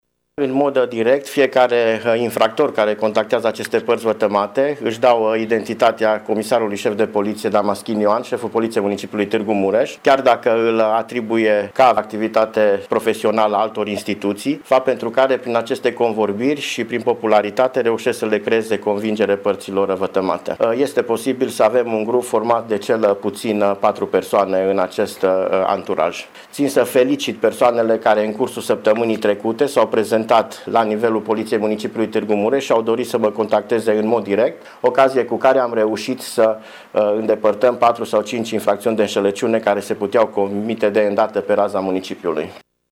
Ioan Damaschin este şeful Poliţiei Tîrgu-Mureş şi spune că săptămâna trecută mai multe persoane au venit la sediul IPJ şi l-au informat despre metoda folosită de infractori: